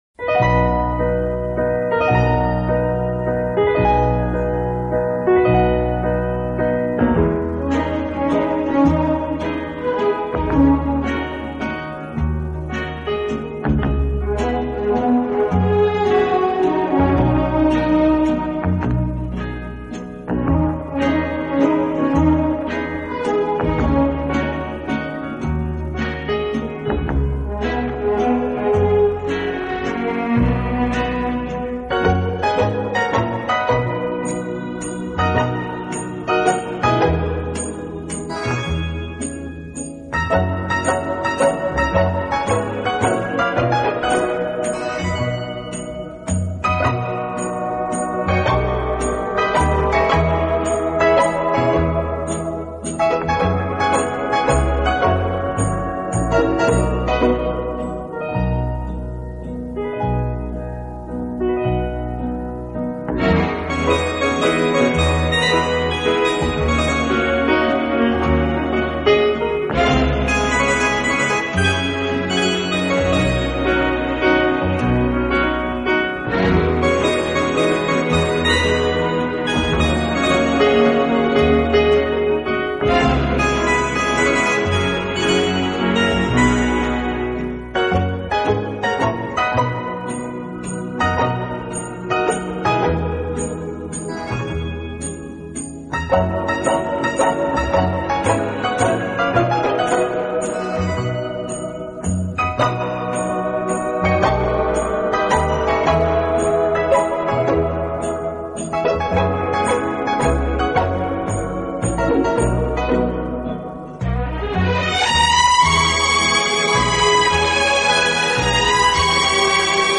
【顶级轻音乐】
“清新华丽，浪漫迷人”